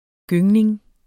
Udtale [ ˈgøŋneŋ ]